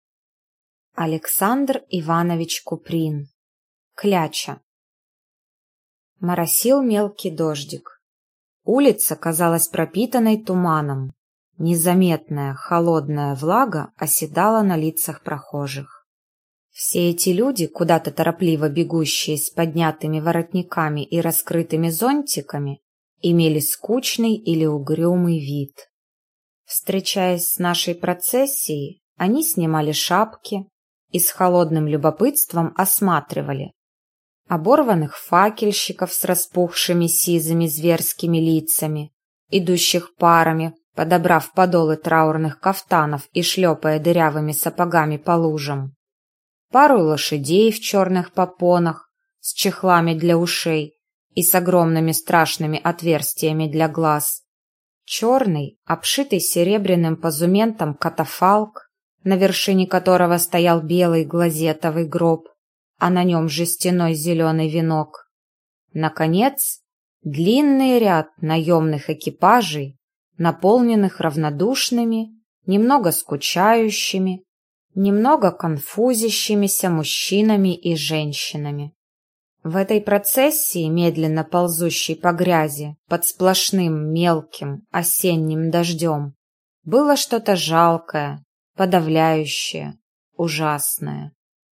Аудиокнига Кляча | Библиотека аудиокниг